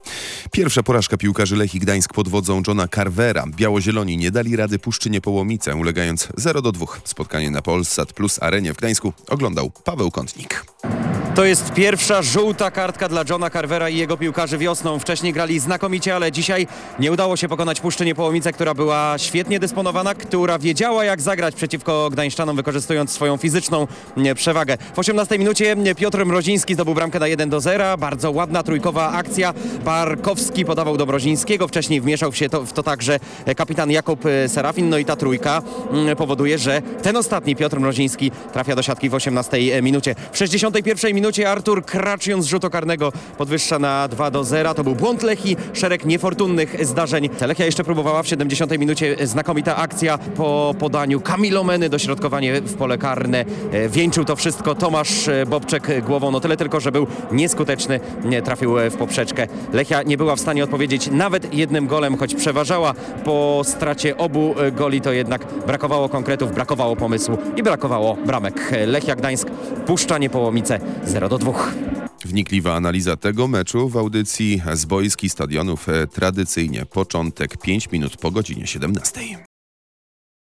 prosto ze stadionu